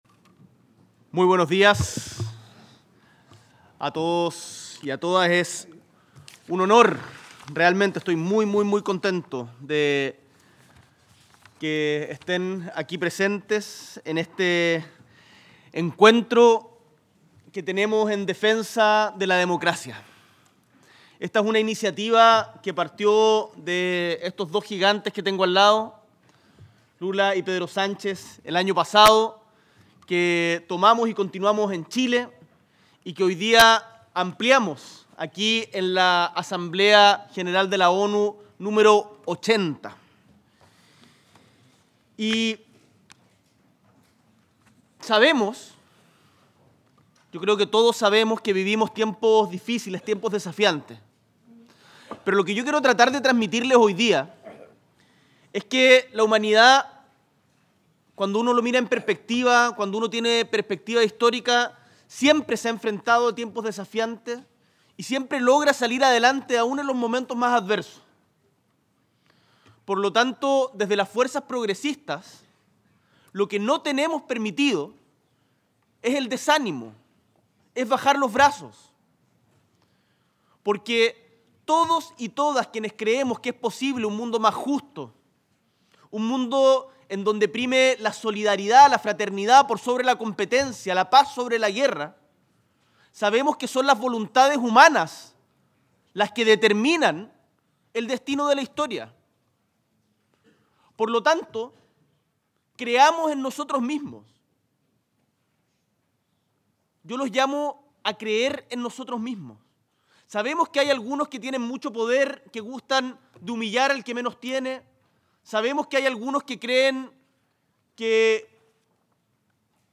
En este evento, coorganizado con Brasil, España, Uruguay y Colombia, el Presidente de la República, Gabriel Boric Font, ofició como moderador.